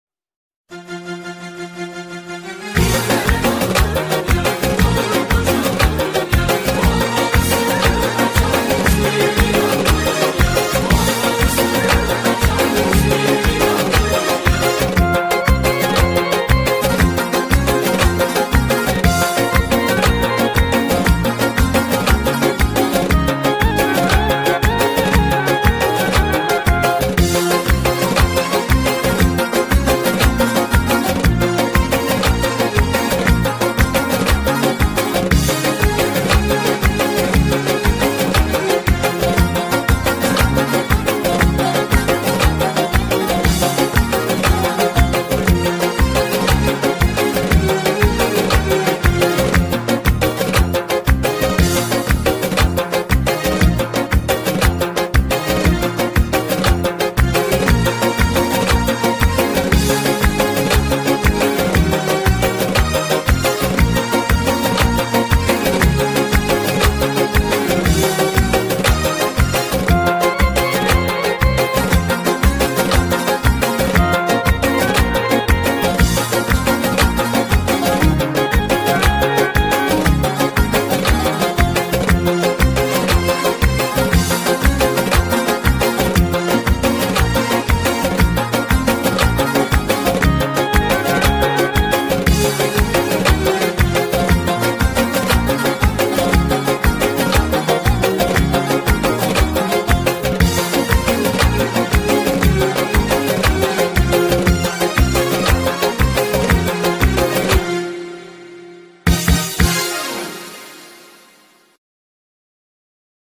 بی کلام نوازندگی ارگ